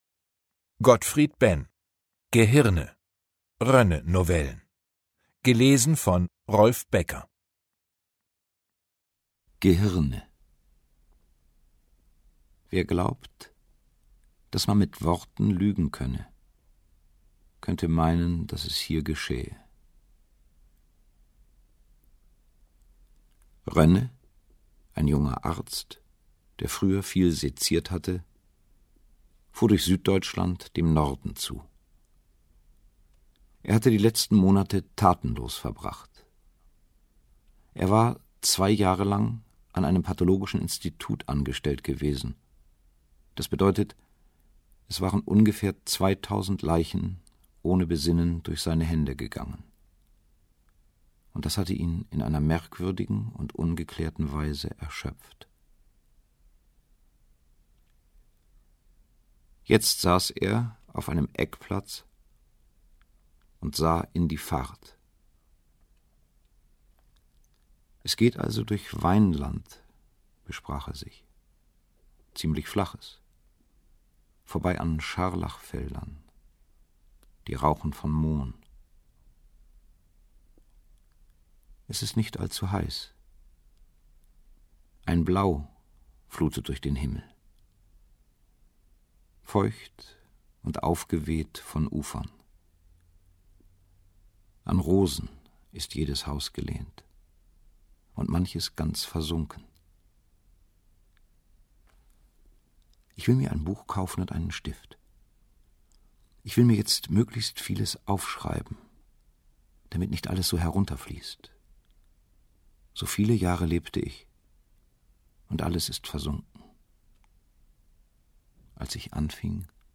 Rolf Becker (Sprecher)
Gekürzte Lesung